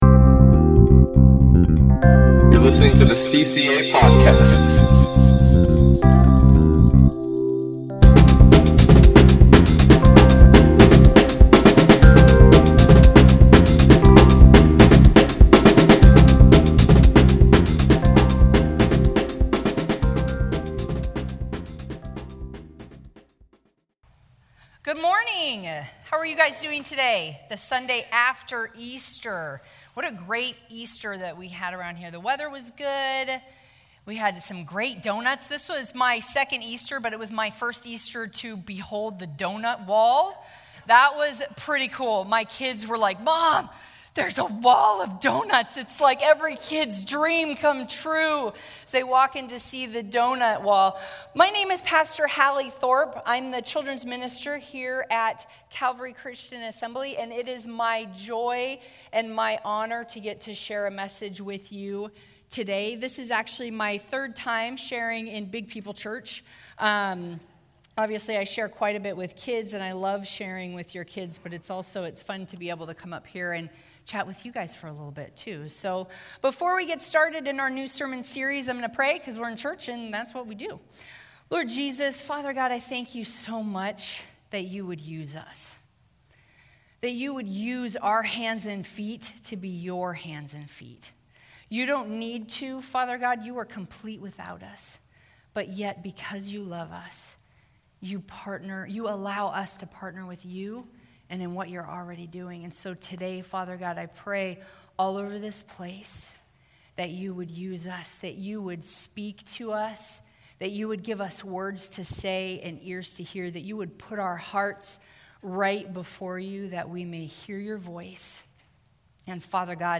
CCA kicks off a new sermon series: How To Neighbor.